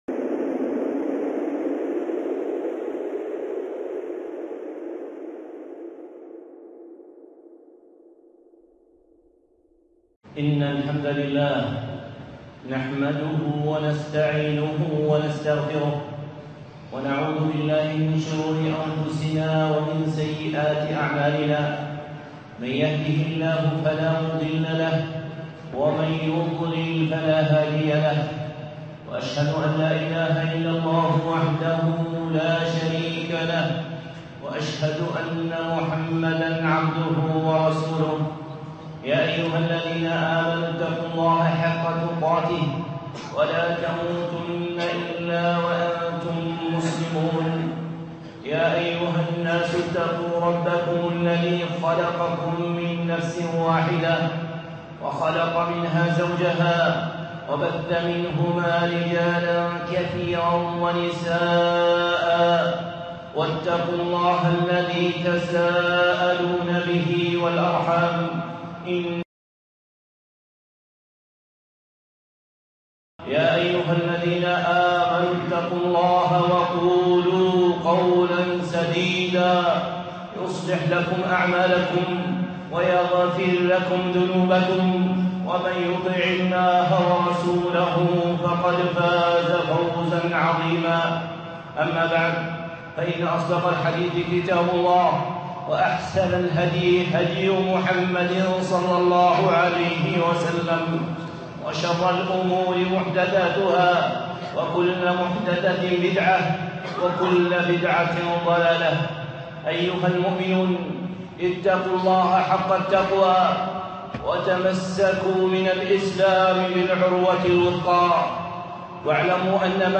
خطبة (إن ربك واسع المغفرة)